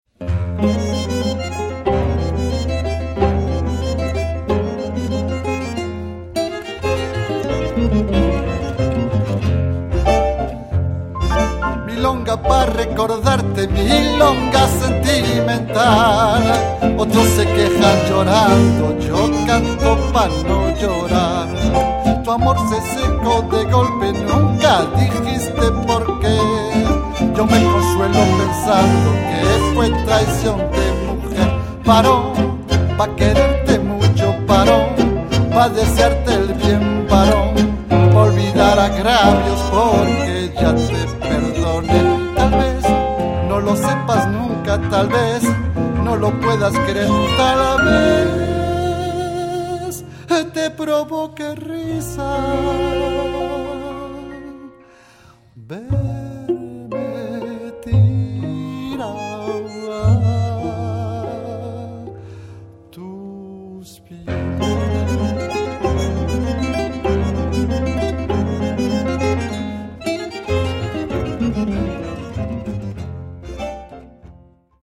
fonctionne actuellement comme un quintette de jazz
Guitare/chant/arrangement
Piano
Bandonéon
Violon alto
Contrebasse